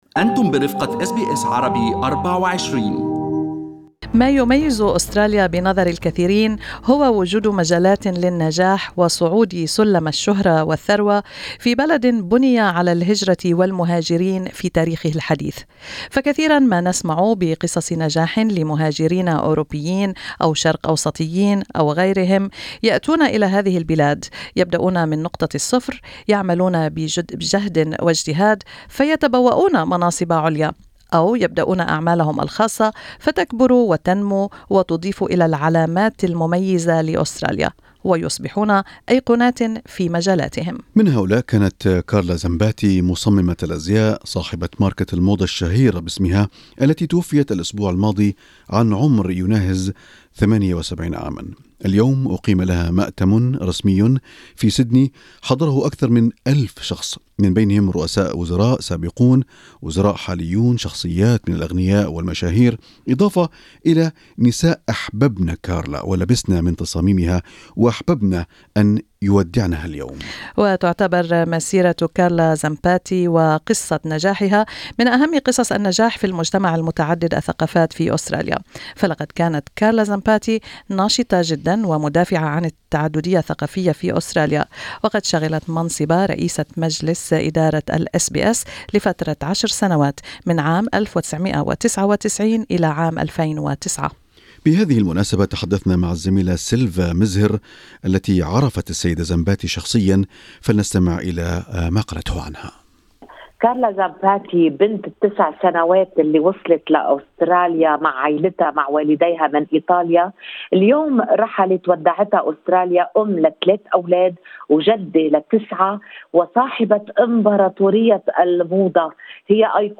لقاء إذاعي